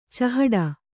♪ cahaḍa